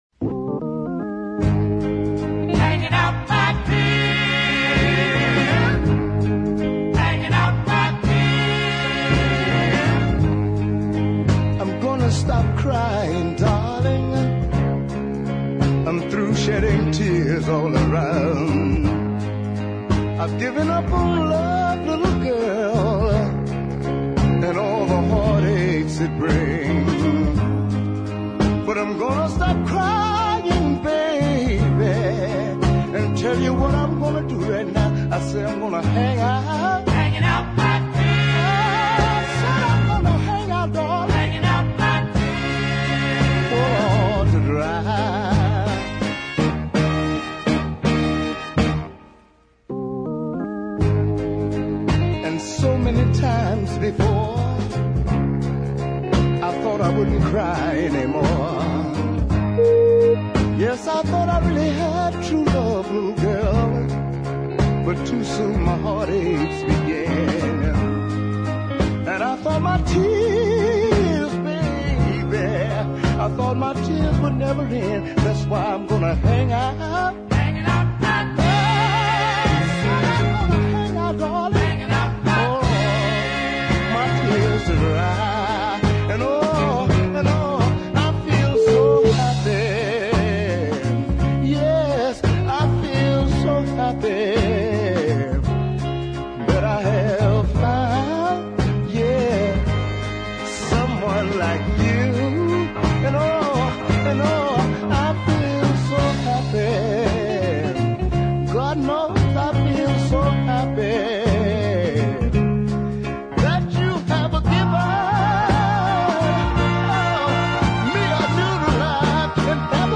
A deep soul piece in the grand manner